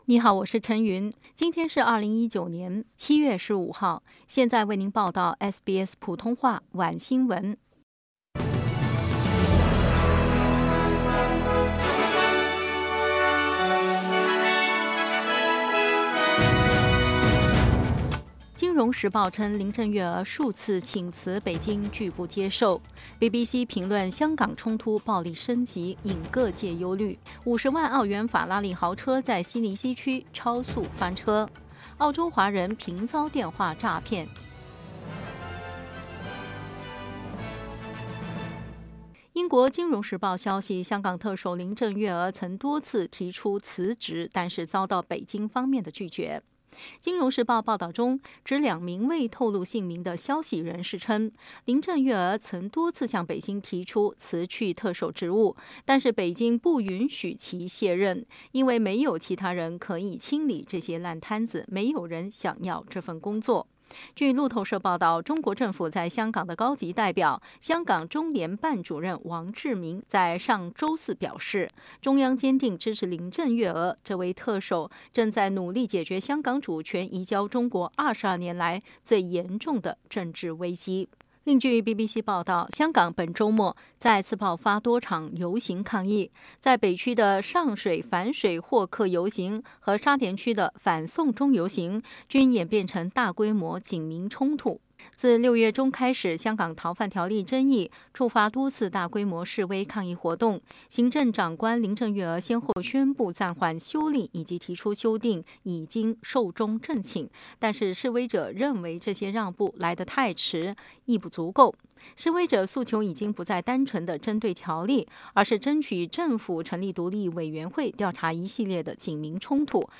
SBS晚新闻（7月15日）